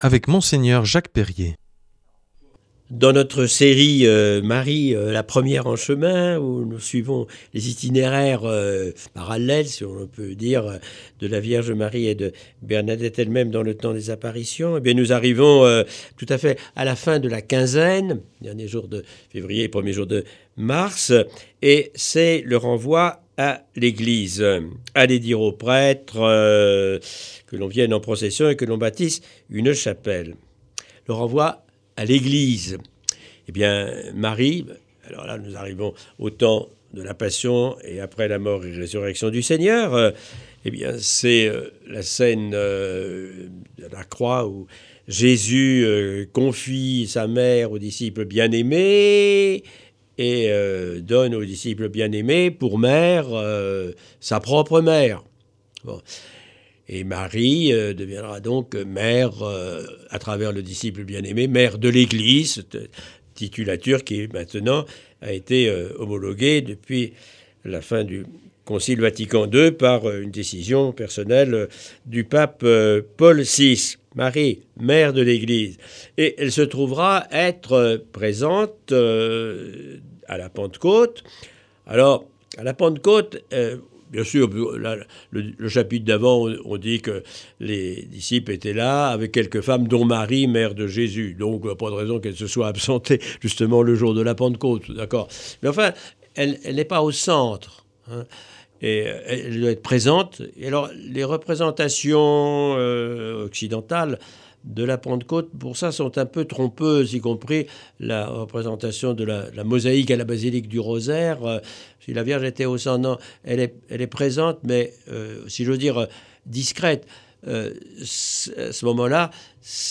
L’enseignement marial de cette semaine nous est proposé par Mgr Jacques Perrier. Il nous amène à faire un parallèle entre l’itinéraire de Bernadette Soubirous à travers les apparitions à Lourdes et la vie de la Vierge Marie.